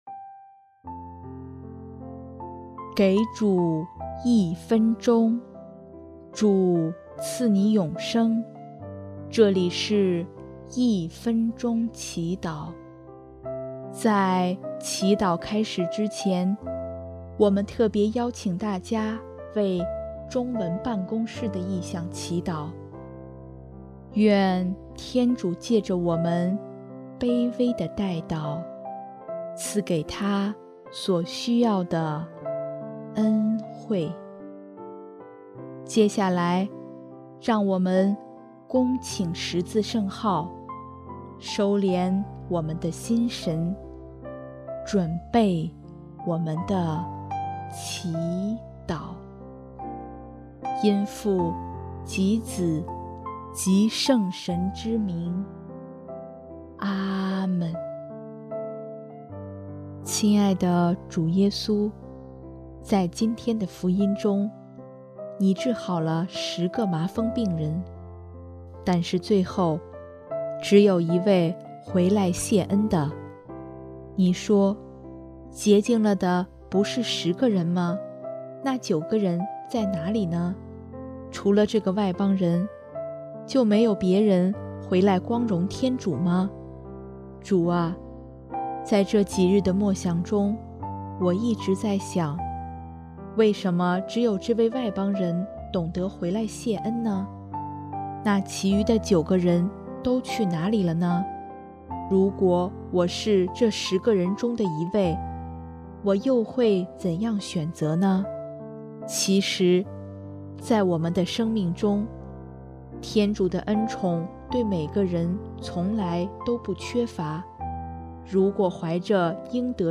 【一分钟祈祷】|11月13日 主，教我做一个知恩报爱的人吧！
音乐：第二届华语圣歌大赛参赛歌曲《感谢赞美主》（中文办公室：感恩天主让大赛圆满结束）